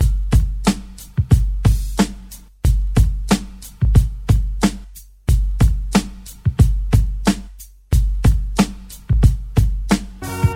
• 91 Bpm 00's Rap Drum Loop G Key.wav
Free drum groove - kick tuned to the G note. Loudest frequency: 747Hz
91-bpm-00s-rap-drum-loop-g-key-T1V.wav